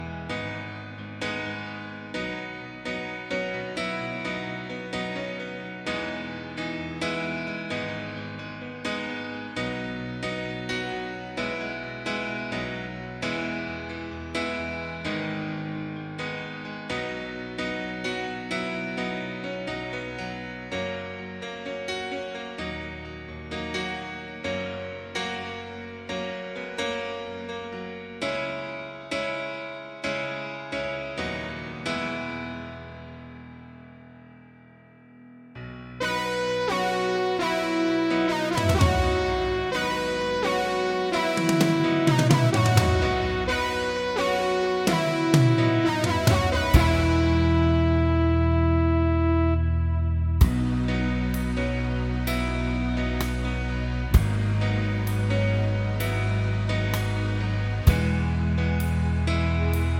Minus Main Guitars For Guitarists 4:25 Buy £1.50